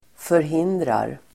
Uttal: [förh'in:drar]